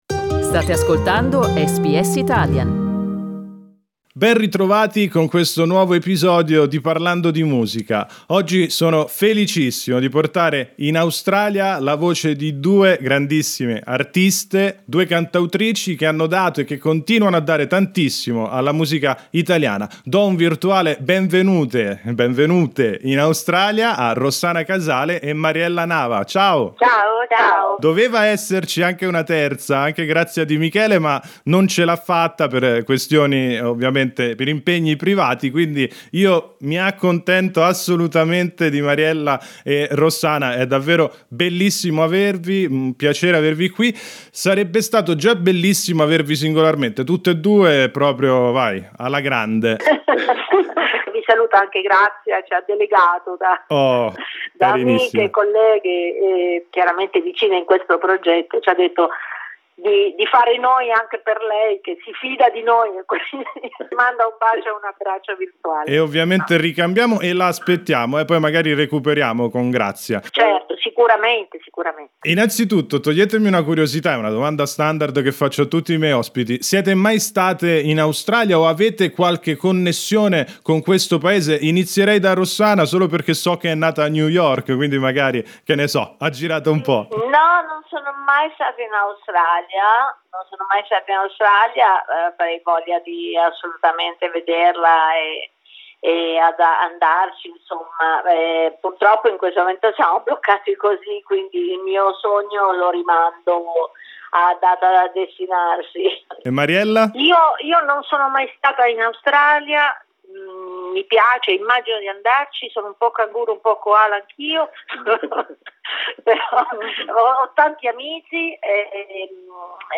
Mariella Nava e Rossana Casale raccontano il loro nuovo progetto musicale assieme a Grazia Di Michele.